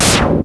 slash01.wav